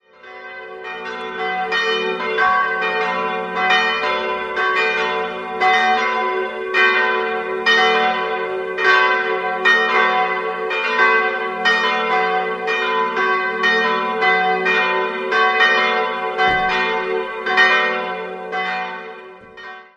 Die Martinskirche wurde im Jahr 1728 vom berühmten Eichstätter Barockbaumeister Gabriel de Gabrieli umgebaut und dem Ortsbild angepasst. Die drei Altäre im Innenraum stammen ebenfalls aus dem Jahr 1728. 3-stimmiges TeDeum-Geläute: g'-b'-c'' Die Glocken wurden 1950 von Karl Czudnochowsky in Erding gegossen.